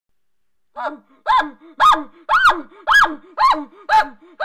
Bull Elk Sounds The Bull Elk make the most magnificent and powerful sounds. This is done to chase off, challenge, and establish dominance over the other Bull Elk. The Chuckle
the_chuckle.wma